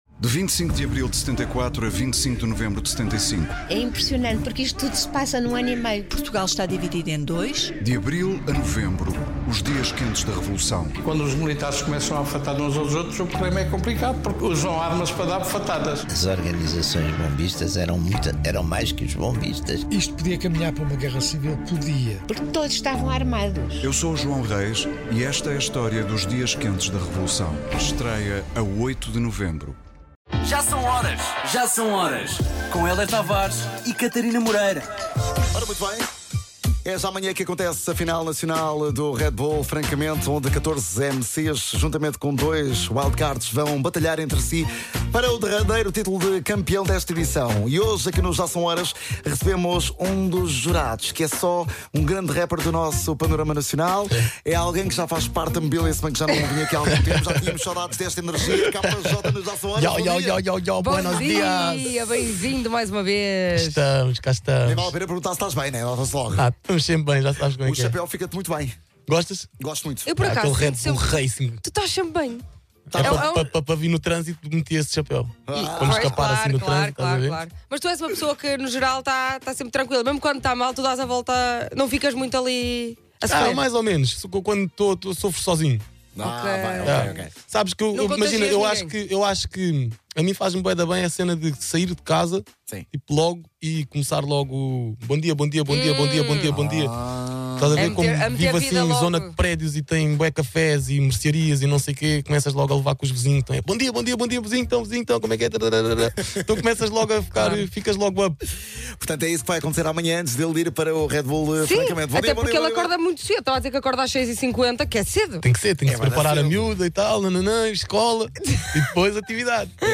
O Kappa Jotta veio até ao estúdio da Cidade FM falar sobre o seu papel como júri na final nacional do RED BULL FRANCAMENTE.
entrevista_kappa_jotta_red_bull__50ea67d8_normal.mp3